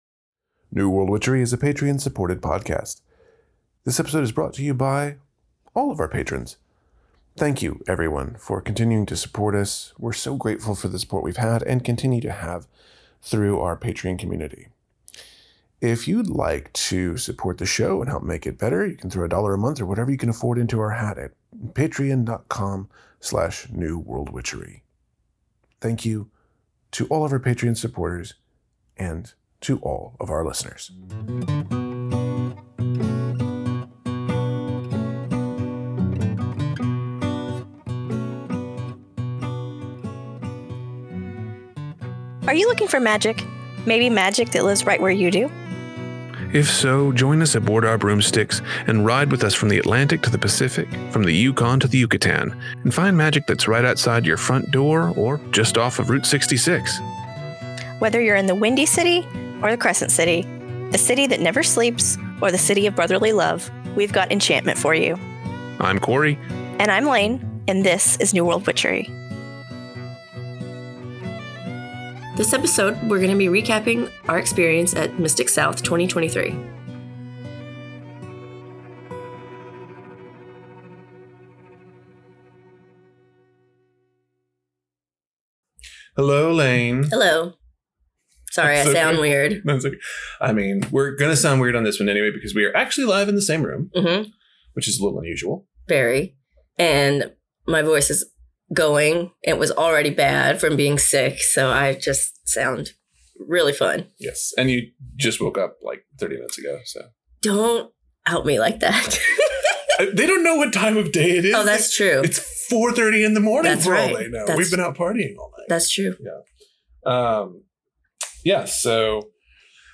Episode 232 – Live from Mystic South 2023